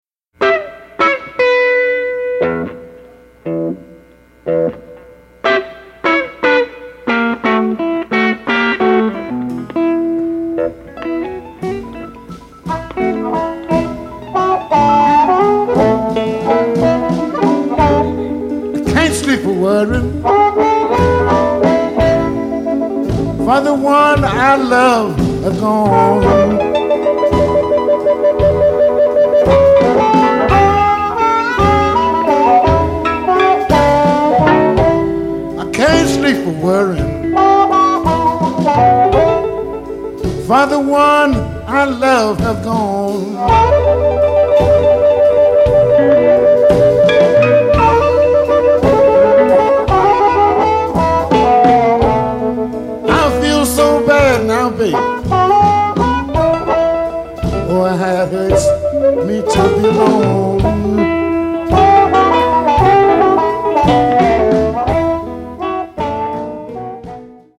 Recorded in the 1980's and 1990's